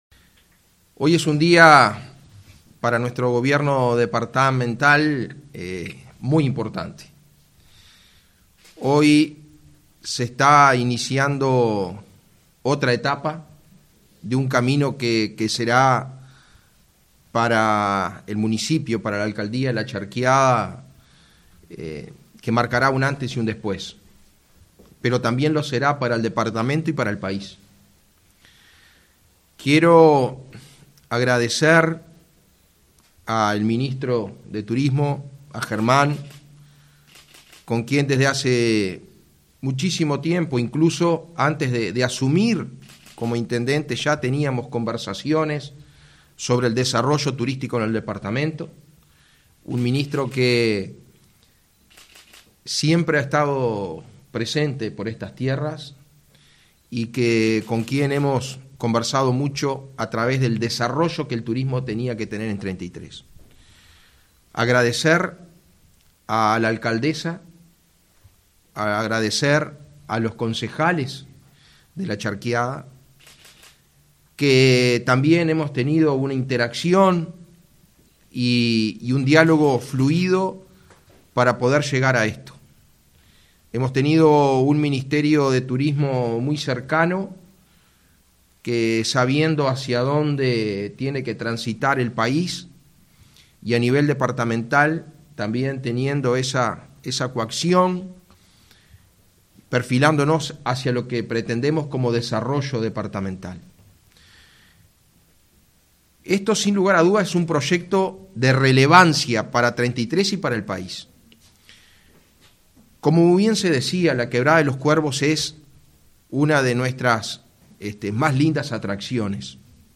Declaraciones del intendente de Treinta y Tres, Mario Silvera, en La Charqueada